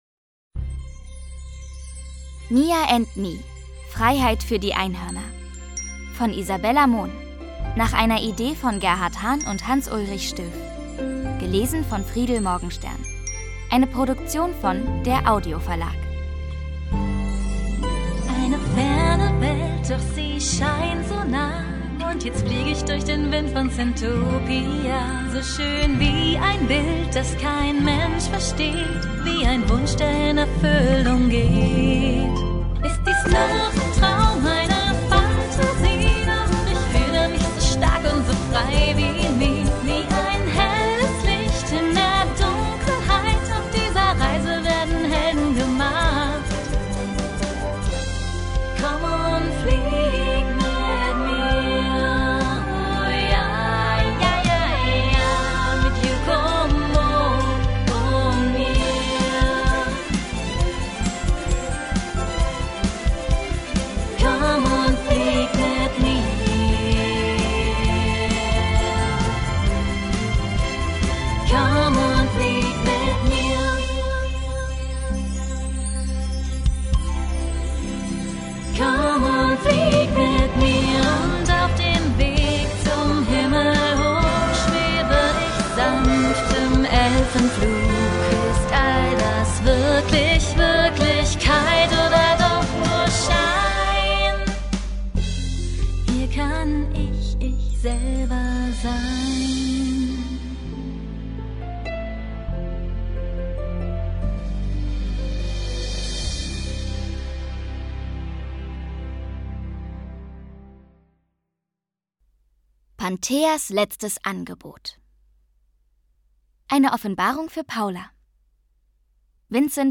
Lesung mit Musik